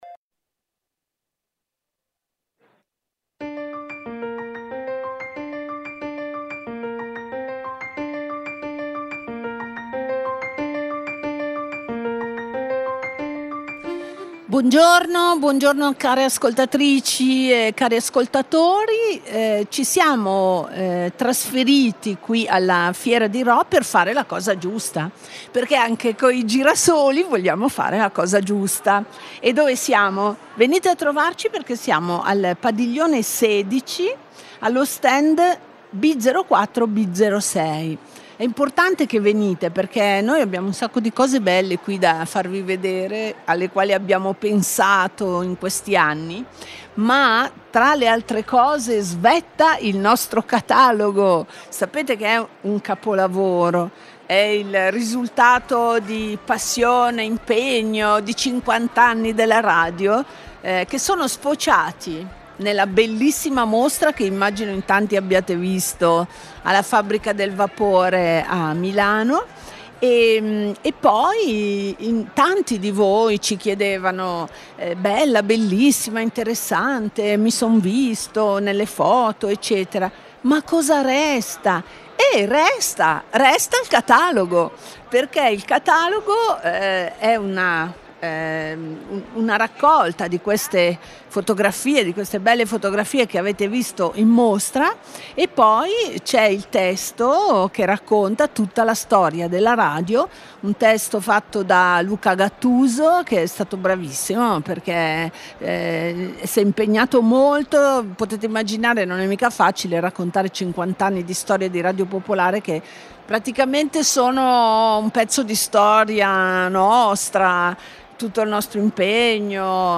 Ogni sabato alle 13.15, il programma esplora eventi culturali, offre interviste ai protagonisti dell'arte, e fornisce approfondimenti sui critici e sui giovani talenti.